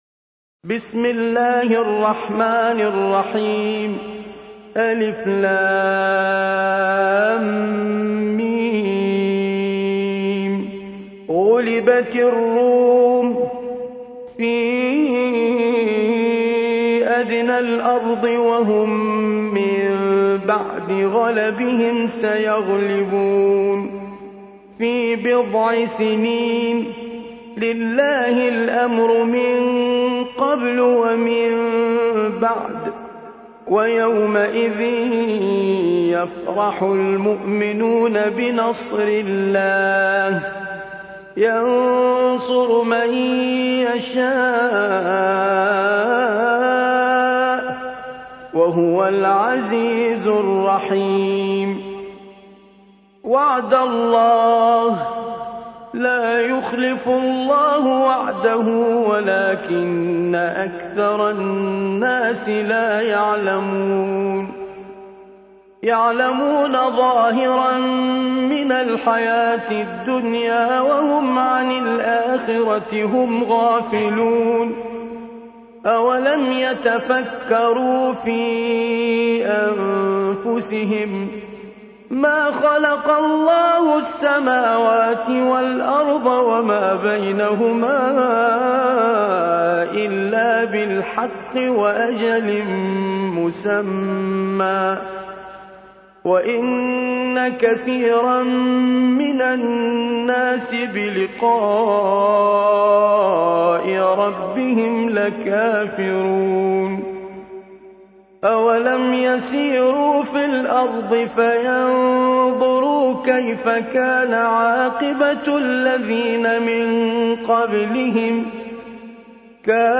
30. سورة الروم / القارئ